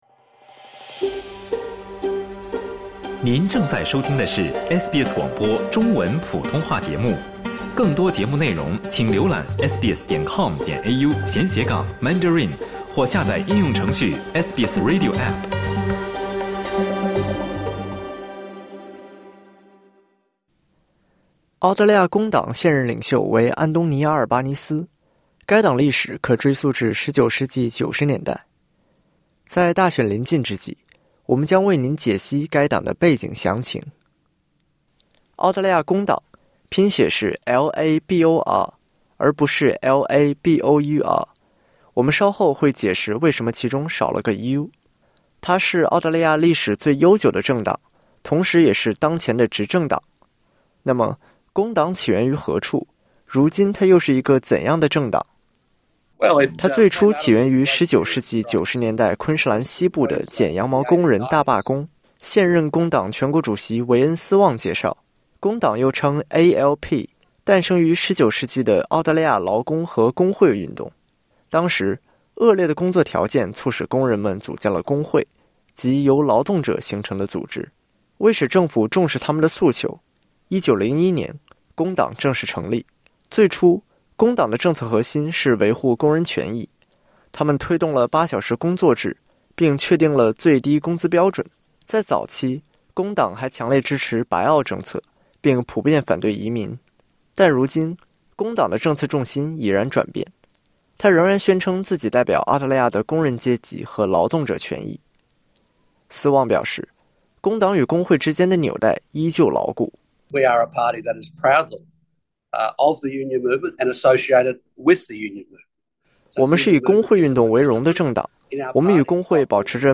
前国库部长、现任工党全国主席韦恩·斯旺（Wayne Swan）介绍。